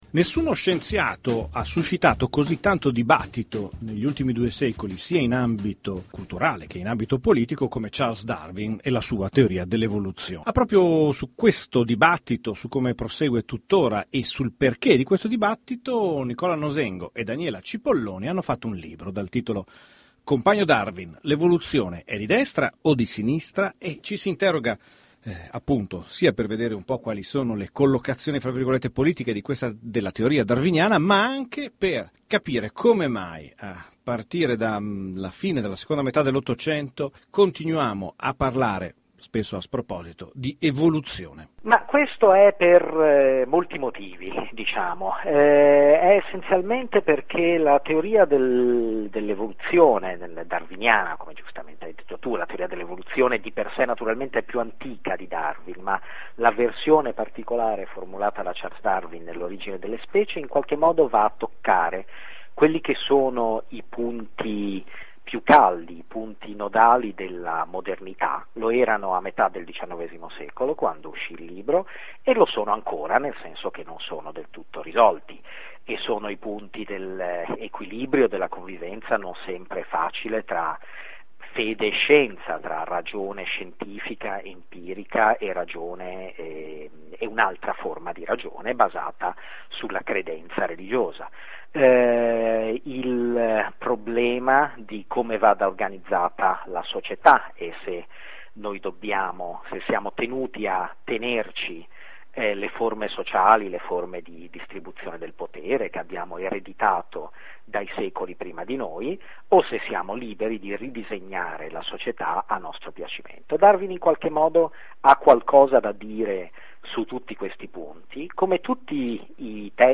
Ecco l’intervista